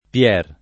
Pier [ p L$ r ] tronc. di Piero